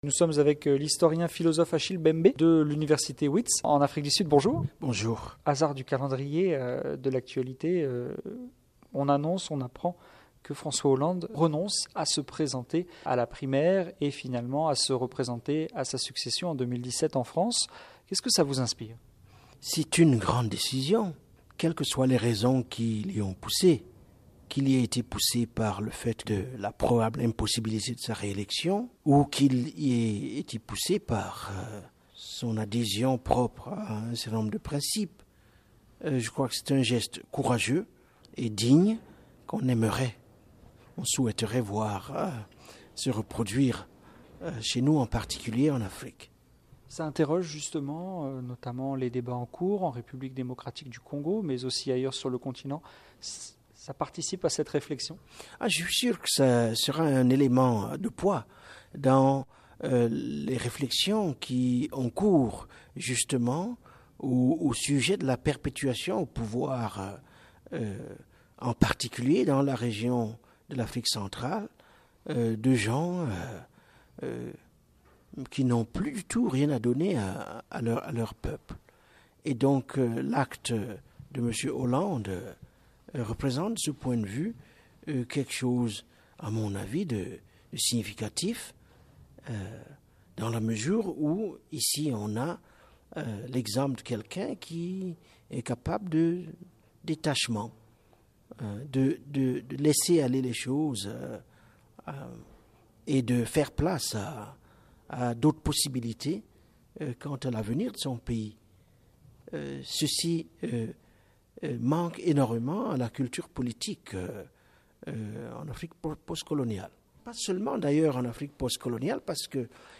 De passage à Washington, l'intellectuel camerounais, professeur d’histoire et philosophe, s'est confie à VOA Afrique : alternance politique en Afrique, mondialisation et élection de Donald Trump, etc. Entretien.